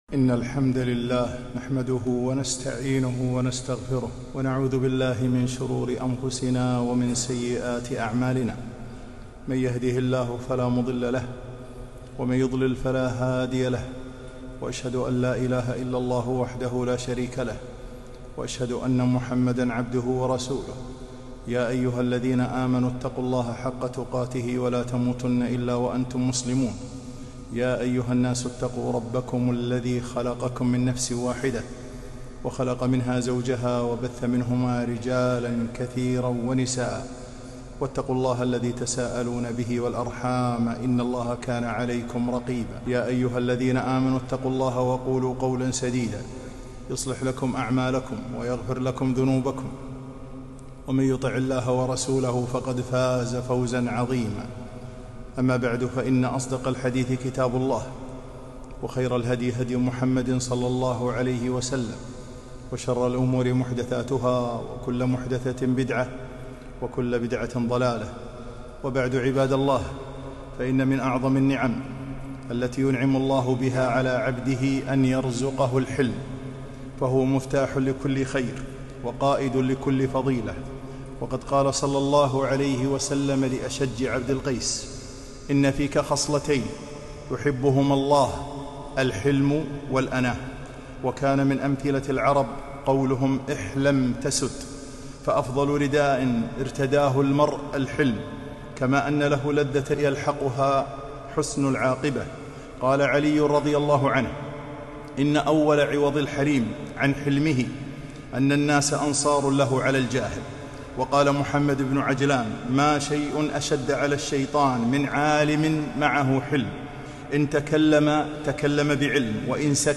خطبة - مدح الحلم وذم الغضب